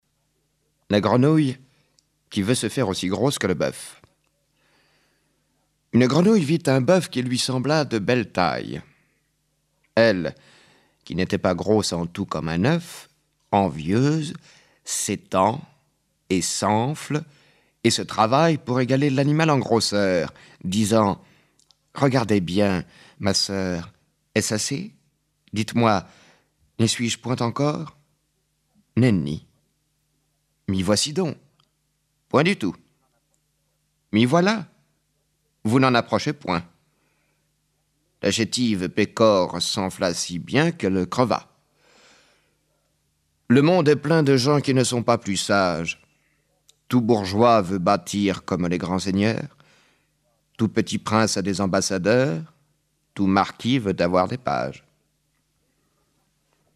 フランス人による朗読音声